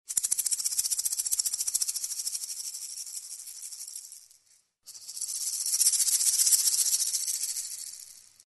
Звуки маракасов
Быстрое встряхивание маракасами